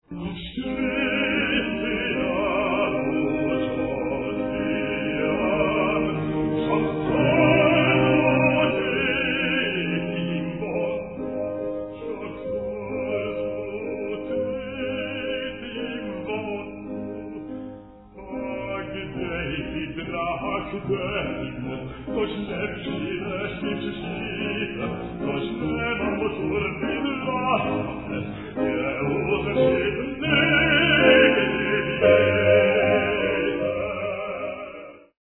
bass baritone
piano